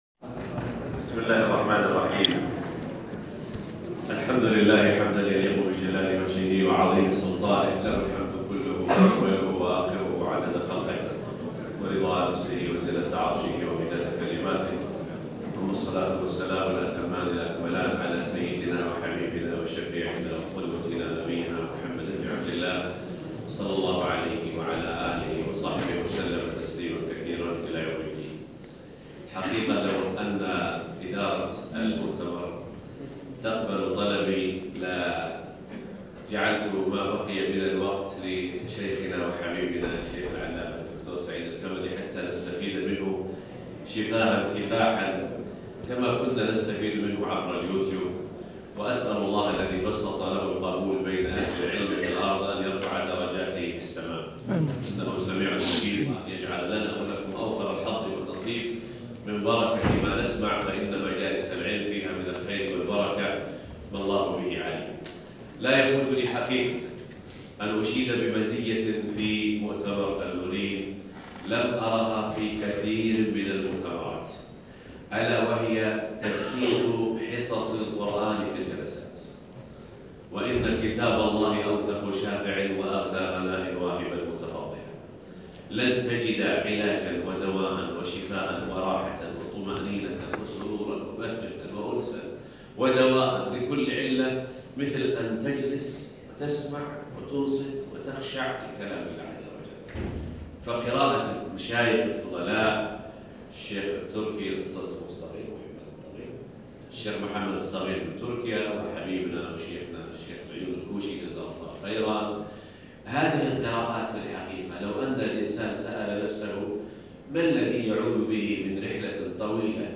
محاضرة 2